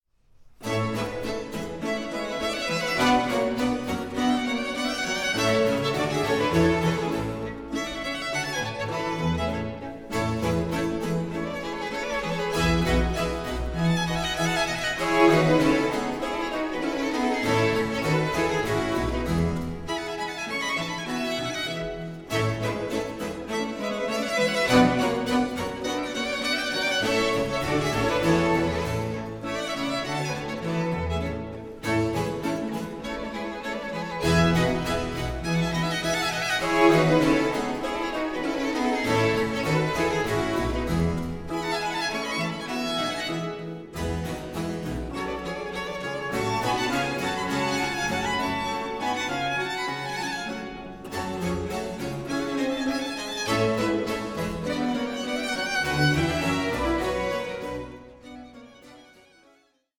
Largo 1:11